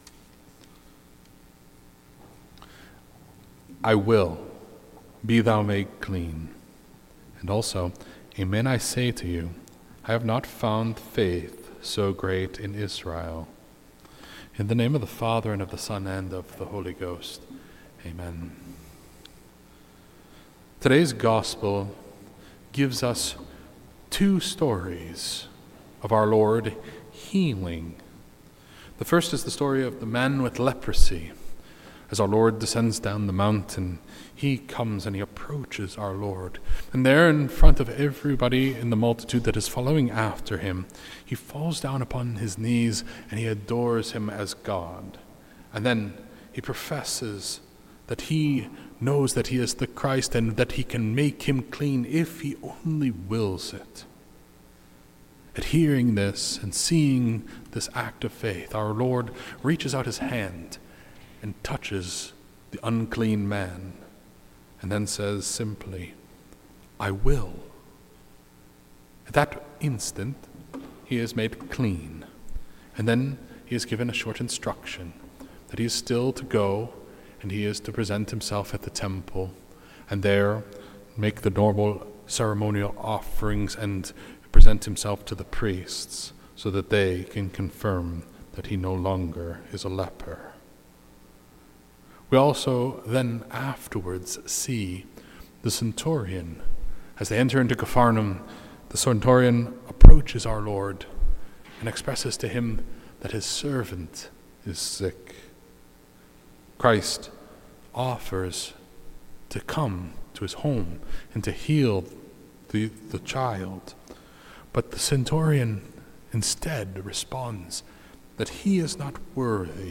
This entry was posted on Sunday, January 25th, 2026 at 1:48 pm and is filed under Sermons.